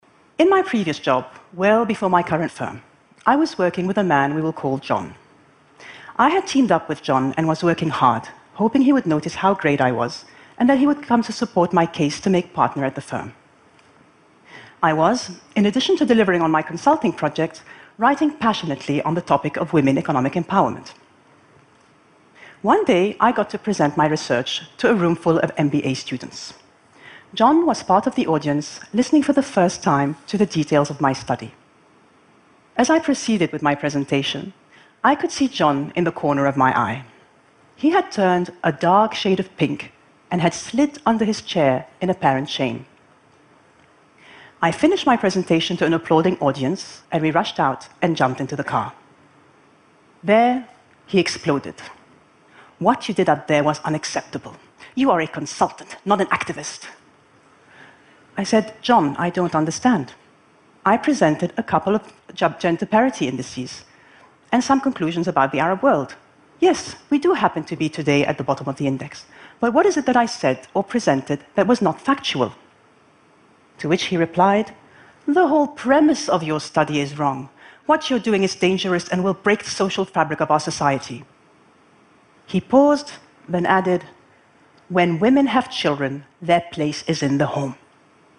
TED演讲:来自阿拉伯女商人的成功经验(3) 听力文件下载—在线英语听力室